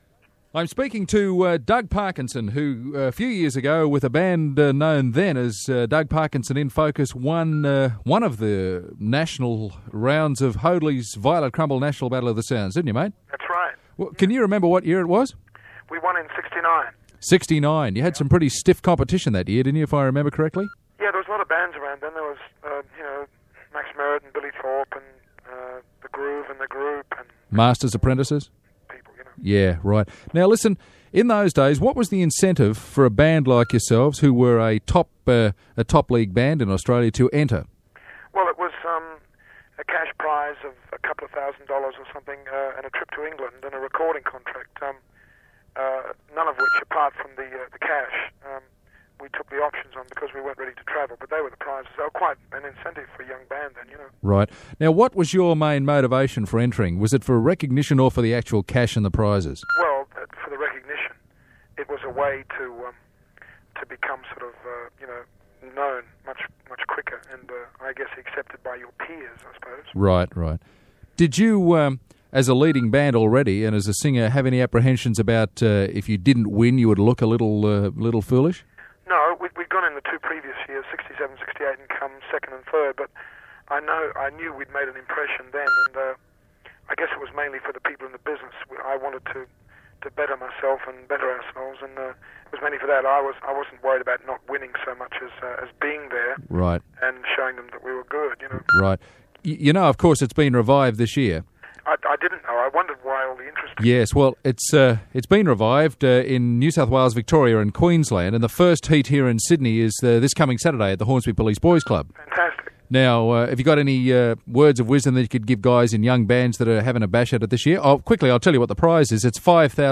82759-doug-parkinson-radio-interview